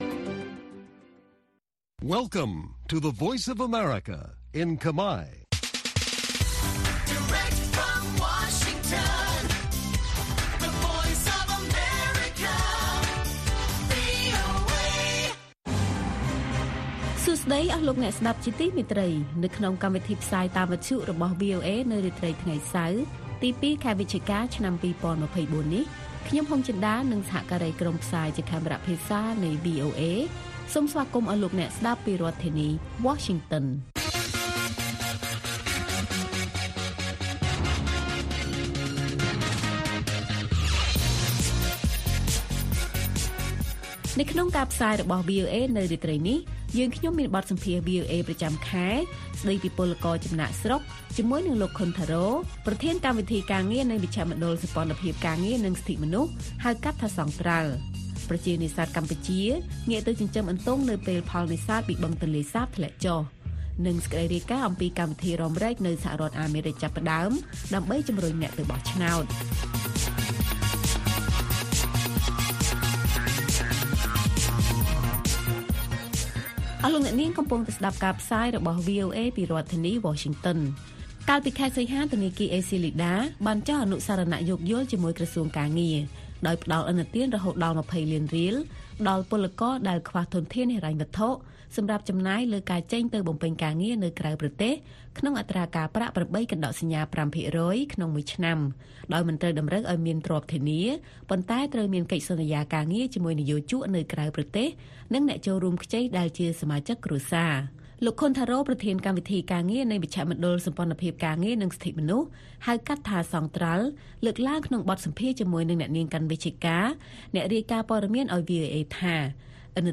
ព័ត៌មានពេលរាត្រី